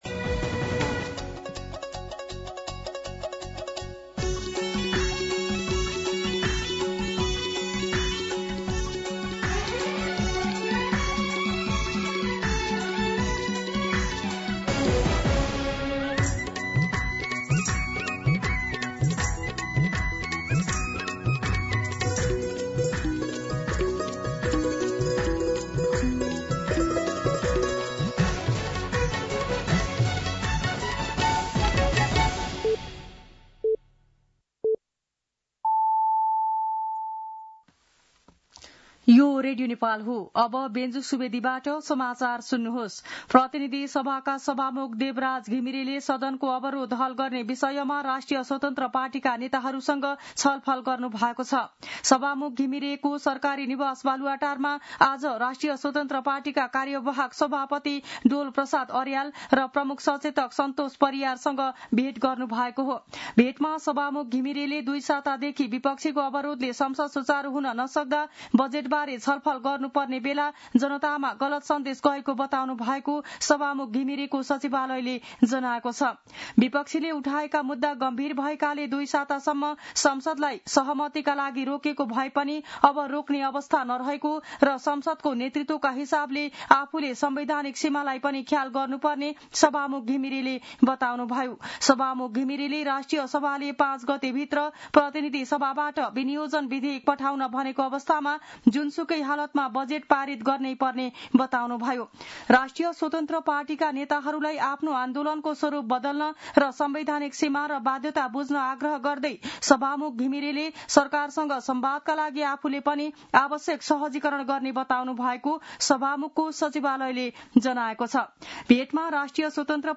मध्यान्ह १२ बजेको नेपाली समाचार : २ असार , २०८२